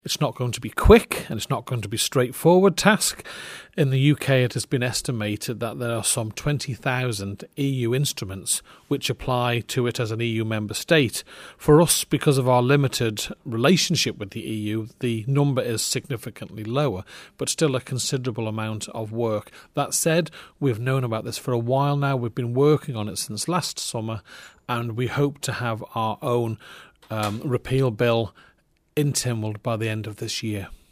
Howard Quayle MHK says the process won't be easy: